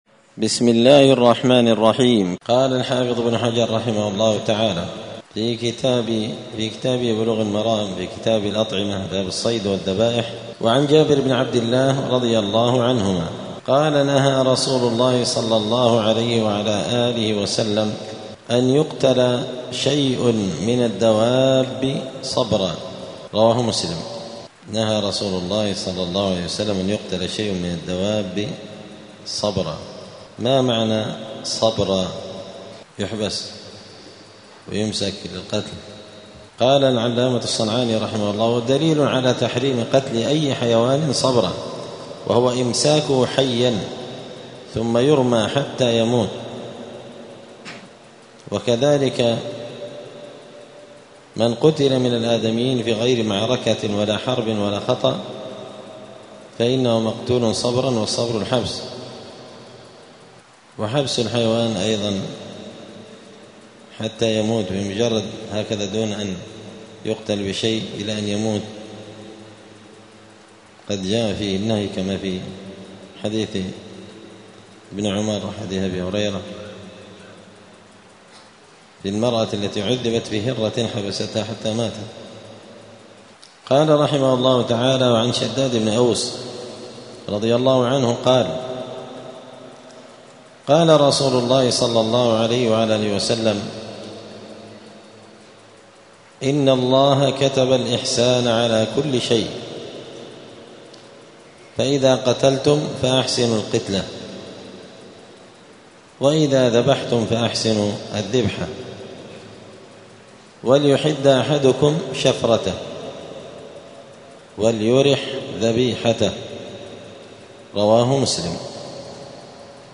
*الدرس السابع عشر (17) {باب الصيد والذبائح ﺗﺤﺮﻳﻢ ﻗﺘﻞ ﺃﻱ ﺣﻴﻮاﻥ ﺻﺒﺮا}*
دار الحديث السلفية بمسجد الفرقان قشن المهرة اليمن